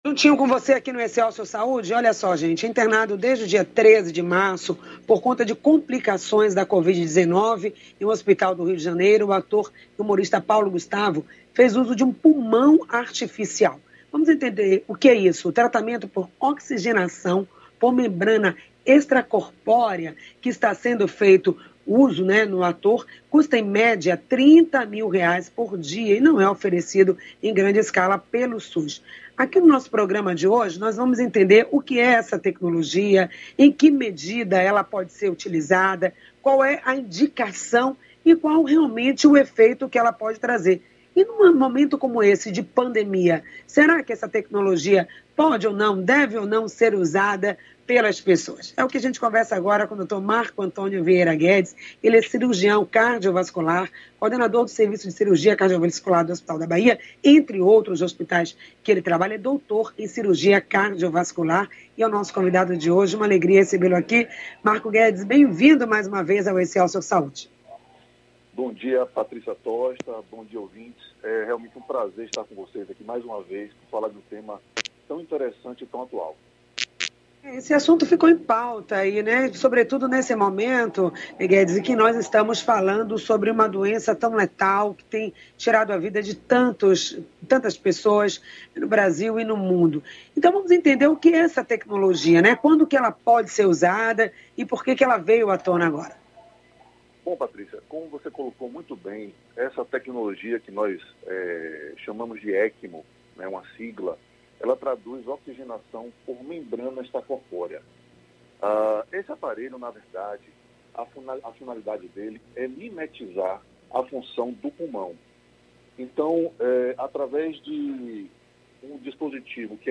O programa Excelsior Saúde que conta com a parceria do Portal Saúde no ar, acontece de segunda à sexta, às 9h, ao vivo com transmissão pela Rádio Excelsior AM 840.
ENTREVISTA-OITO-DE-ABRIL.mp3